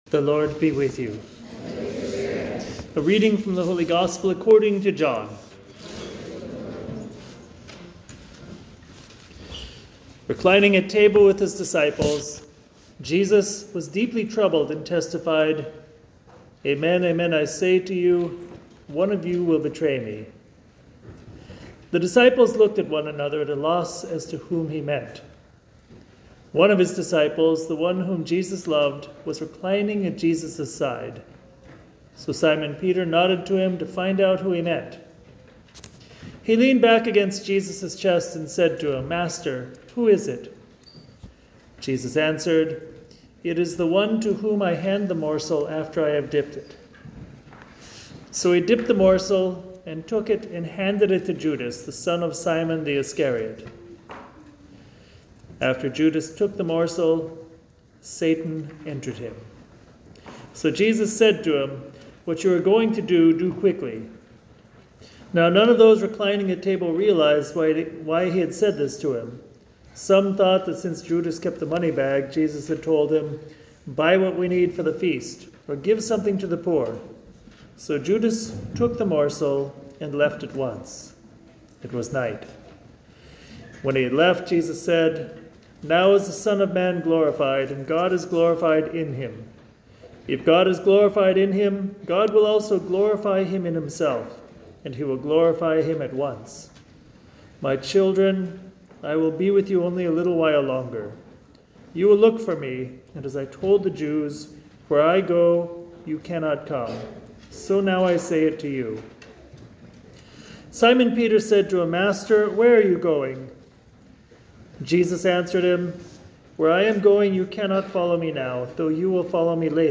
FEN_Homily_Jesus-is-in-Control.mp3